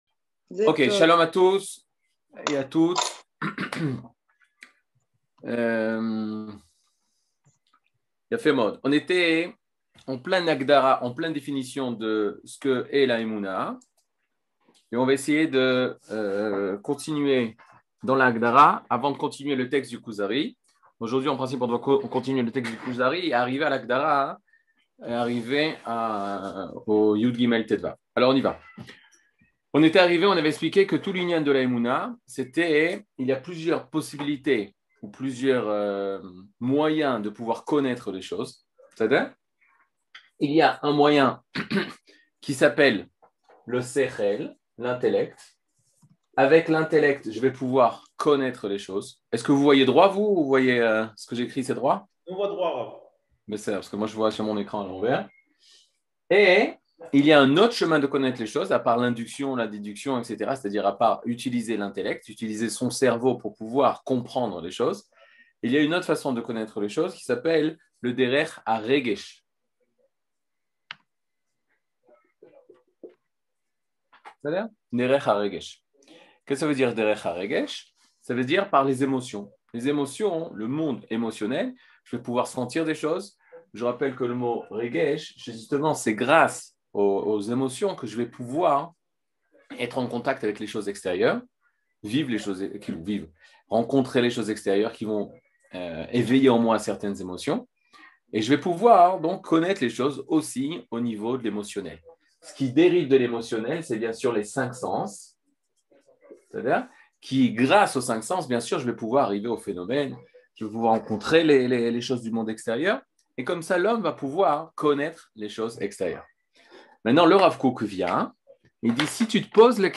Catégorie Le livre du Kuzari partie 18 00:54:22 Le livre du Kuzari partie 18 cours du 16 mai 2022 54MIN Télécharger AUDIO MP3 (49.77 Mo) Télécharger VIDEO MP4 (111.68 Mo) TAGS : Mini-cours Voir aussi ?